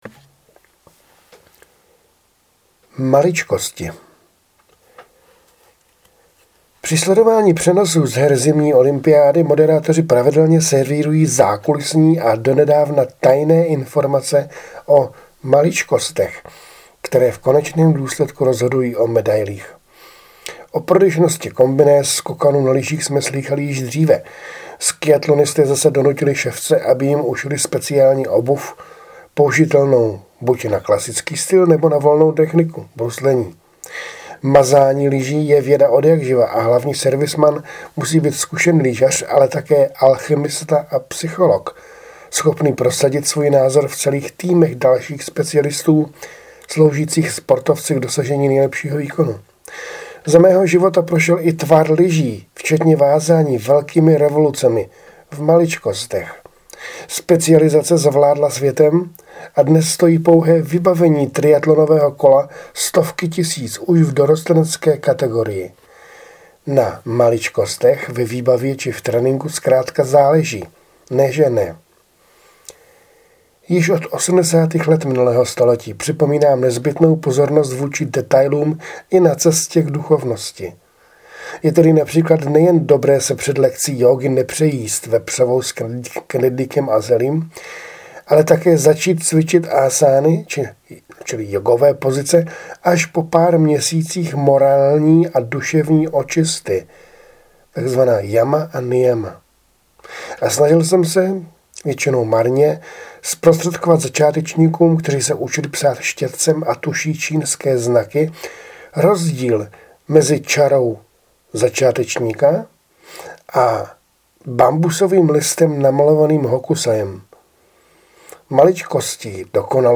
Autorsky namluvený fejeton